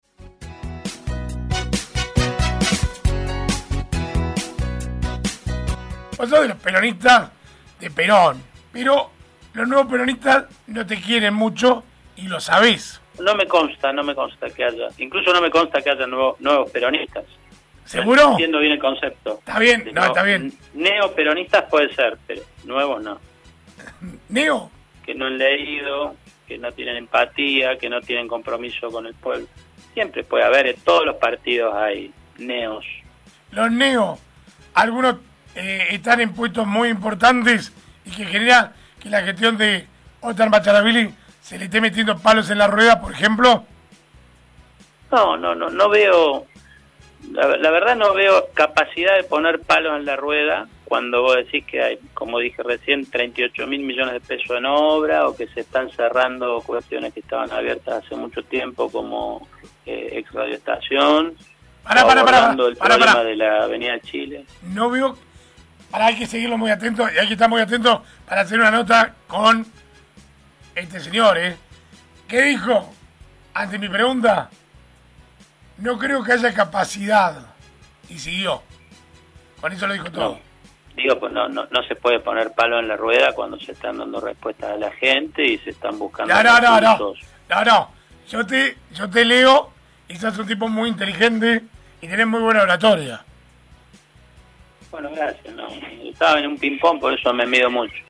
En diálogo con Radiovision, el Secretario de Gobierno Municipal Sergio Bohe, manifestó que “en todos lados existen ‘Neo-peronistas’, como también neo-radicales que aparecen en los gobiernos de turno”.